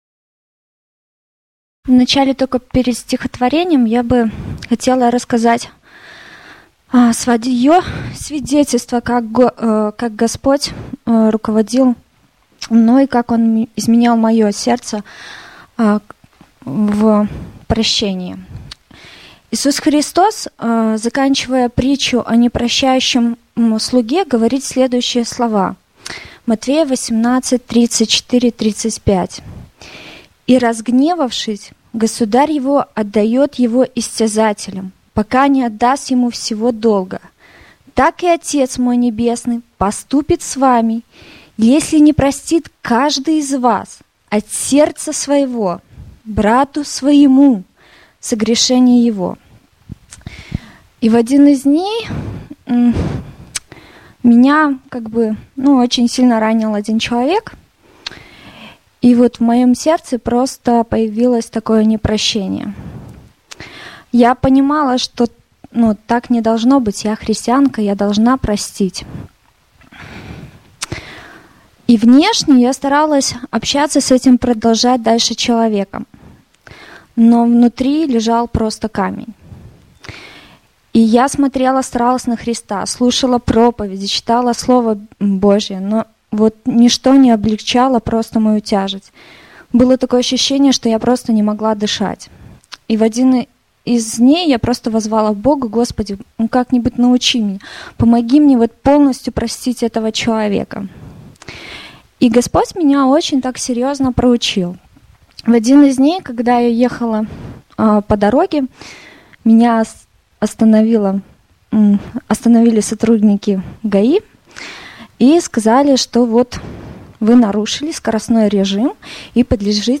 05-10-14 / Прощу. Прощаю… Свидетельство. Стихотворение